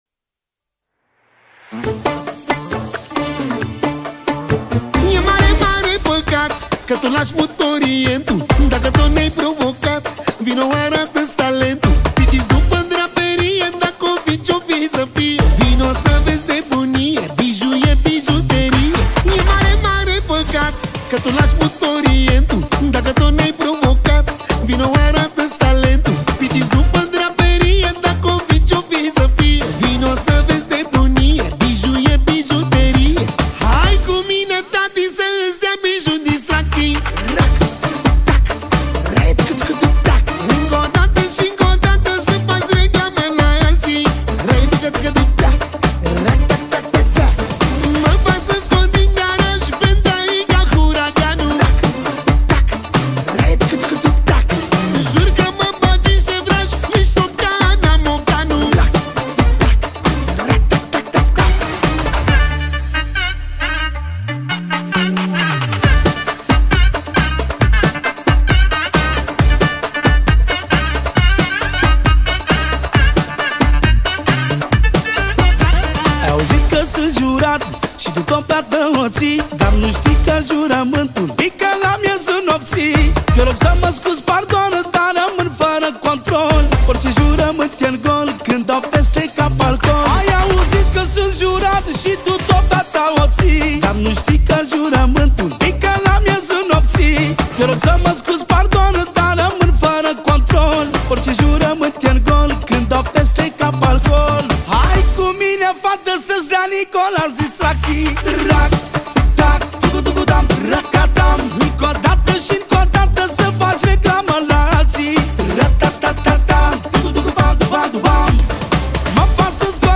Data: 18.10.2024  Manele New-Live Hits: 0